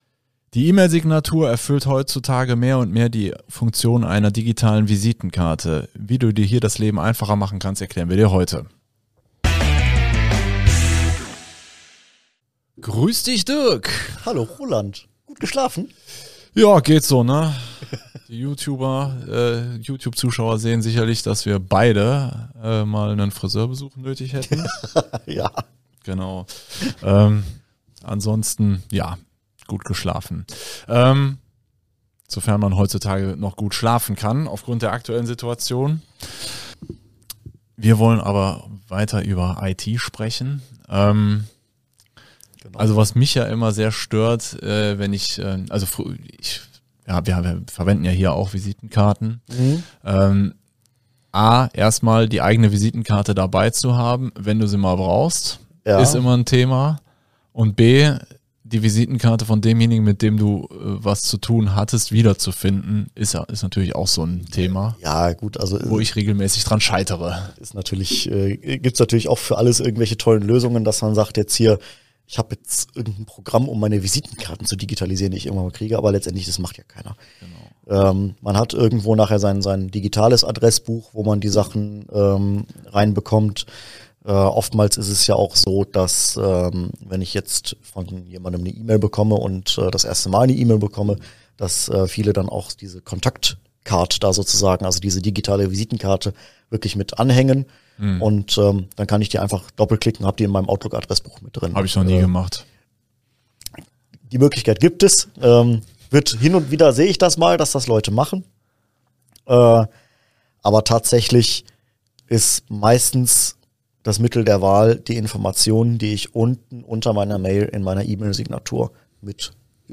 Um dir dieses IT-Wissen zu vermitteln, greifen wir auf eine Doppel-Besetzung zurück: